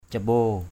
/ca-ɓo:/ (d.) gang trung (từ đầu ngón tay đến ngón tay chỉ) = moyen ampan (longueur de l’extrémité du pouce à l’extrémité de l’index).